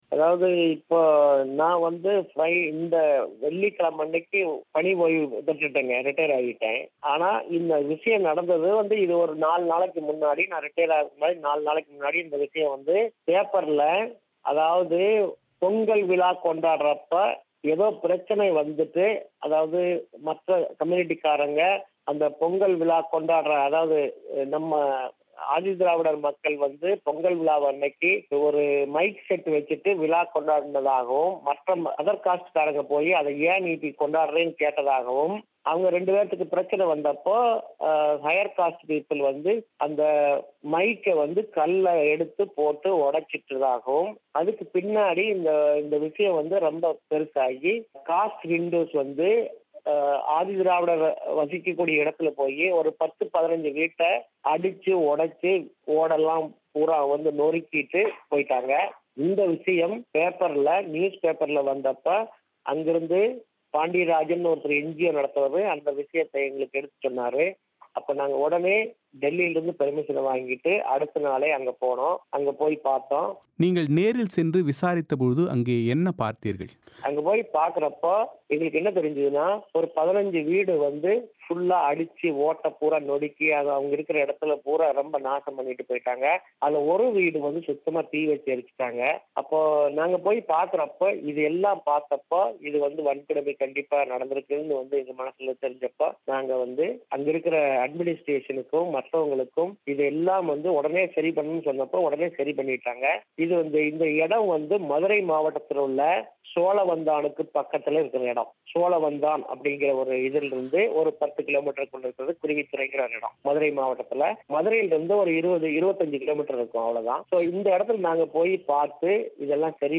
மாநிலத்தின் பெரும்பான்மை மாவட்டங்கள் தலித் வன்கொடுமை ஆபத்திருக்கும் மாவட்டங்களாக நீடிப்பதற்கான காரணங்கள் குறித்தும் அதை போக்குவதற்கான வழிமுறைகள் குறித்தும் அவர் பிபிசி தமிழோசைக்கு அளித்த விரிவான செவ்வியை நேயர்கள் இங்கே கேட்கலாம்.